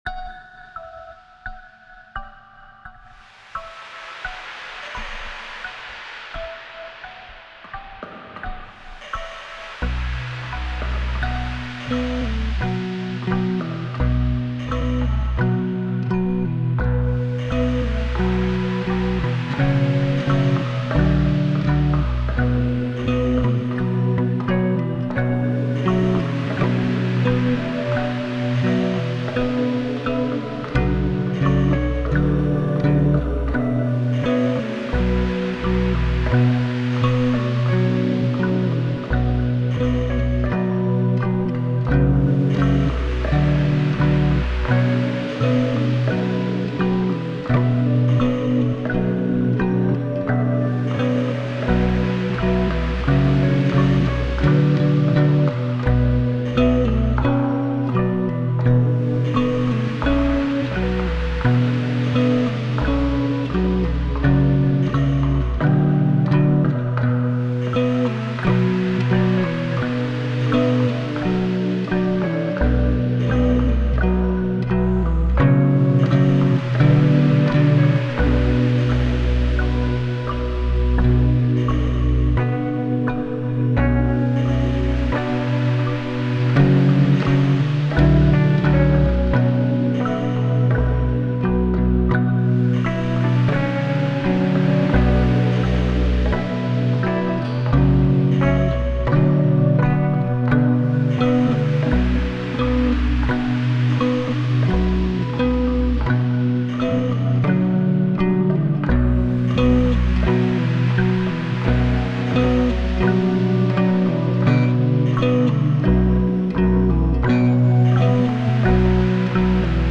[Bac à sable] Impro guitare 01
ça m'a inspiré une petite composition d'ambiance.
J'ai rajouté neuf pistes que j'ai groupé en cinq plus les deux d'origines que j'ai adapté à la duré du morceau avec une petite reverb sur la guitare à la fin du morceau.